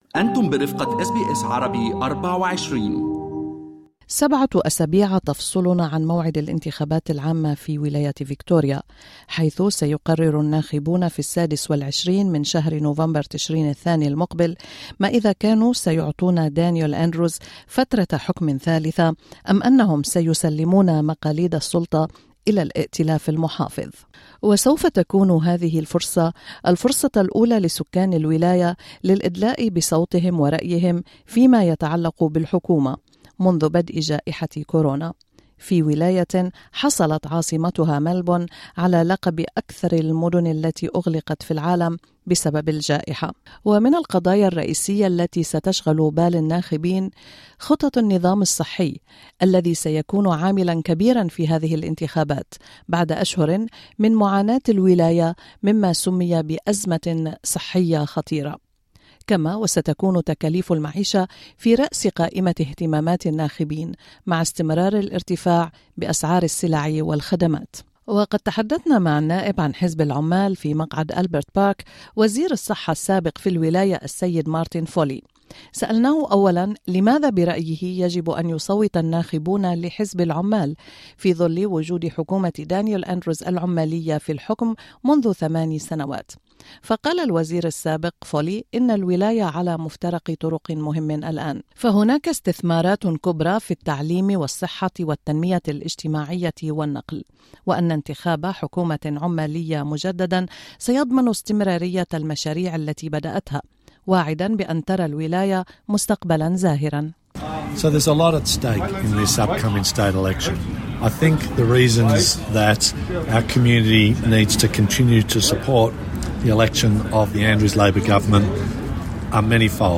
استثمارات كبرى في التعليم والصحة والتنمية الاجتماعية والنقل وفي حديث خاص مع SBS Arabic24 قال وزير الصحة السابق في الحكومة العمالية في فيكتوريا النائب مارتن فولي إن الولاية على مفترق طرق مهم.